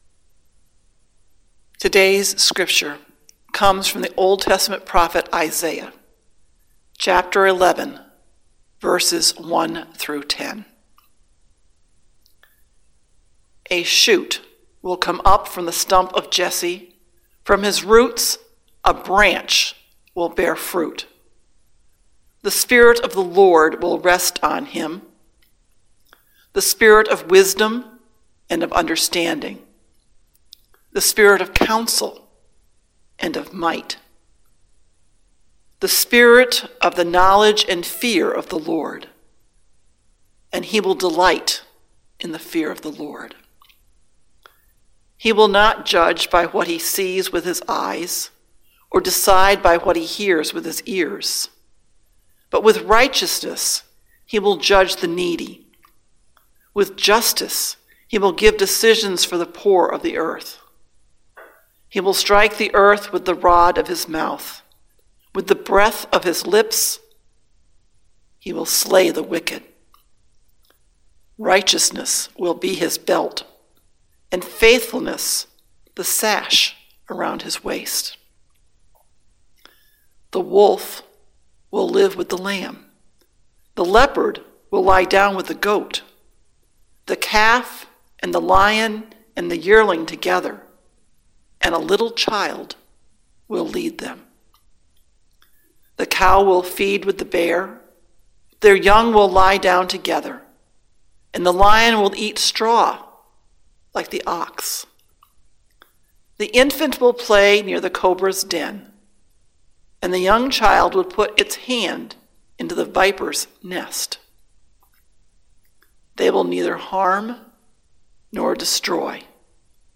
Sermon: New Life from Dead Wood | First Baptist Church, Malden, Massachusetts